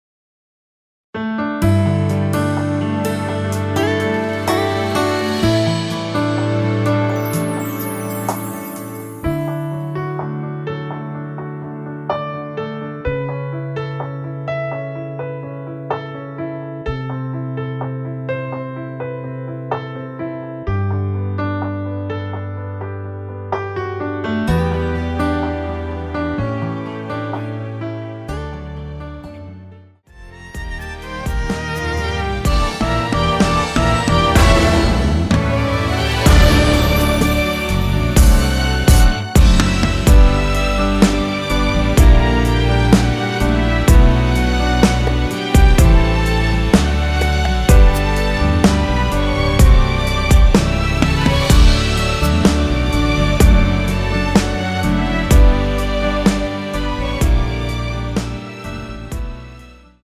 원키에서(-1)내린 MR입니다.
◈ 곡명 옆 (-1)은 반음 내림, (+1)은 반음 올림 입니다.
앞부분30초, 뒷부분30초씩 편집해서 올려 드리고 있습니다.